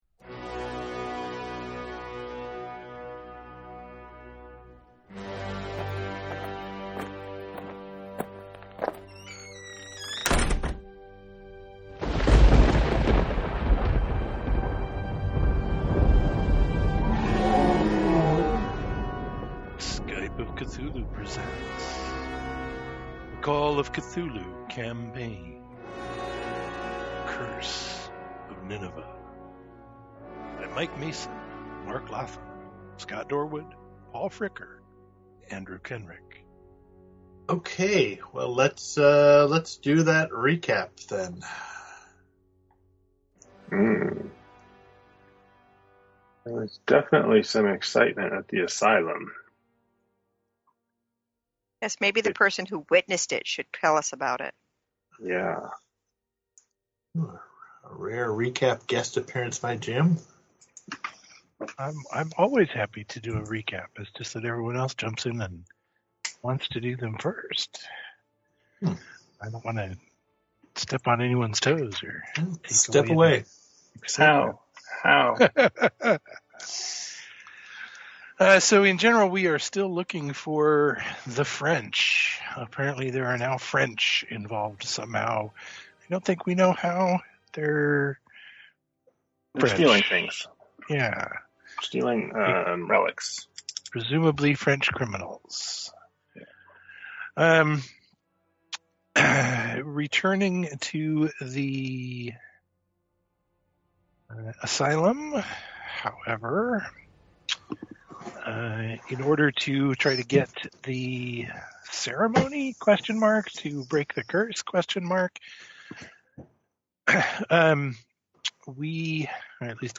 Call of Cthulhu, and perhaps other systems on occasion, played via Skype.